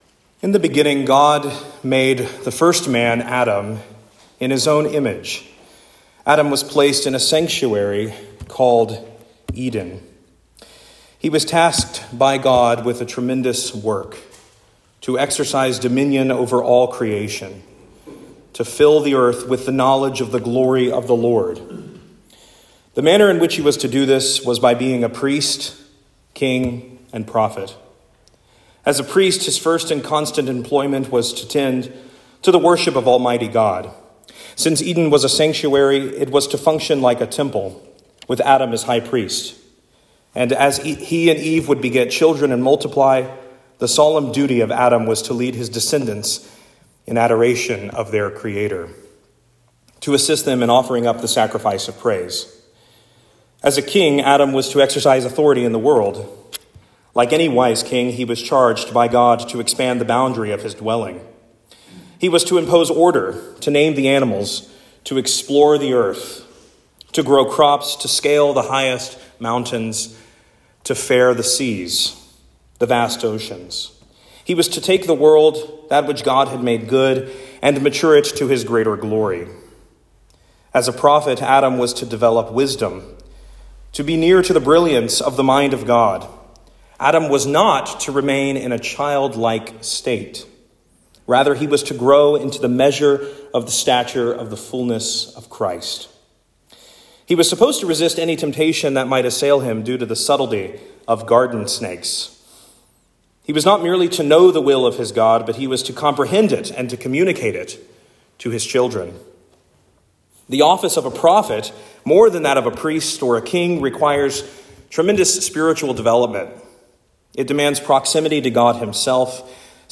Saint George Sermons Sermon for The Sunday Next Before Advent